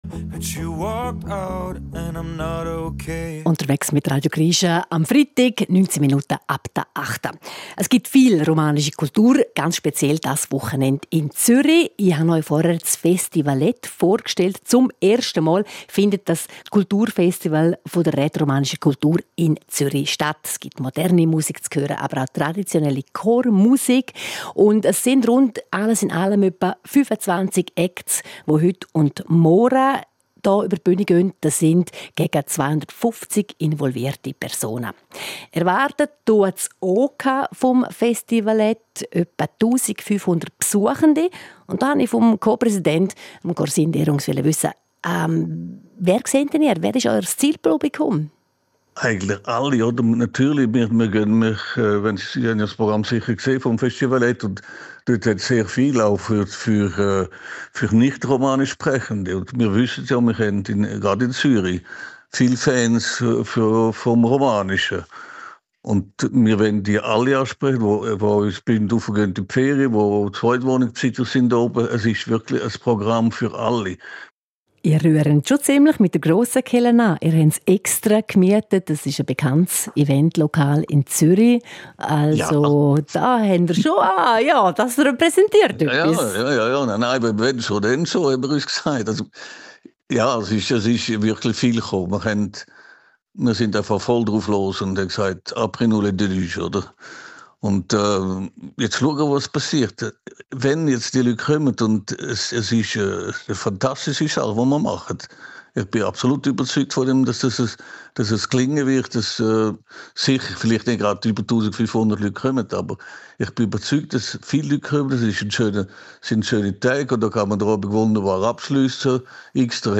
Radiointerview Teil 2 vom 19. Juni 2025 im Tagesprogramm